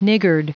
Prononciation du mot niggard en anglais (fichier audio)
Prononciation du mot : niggard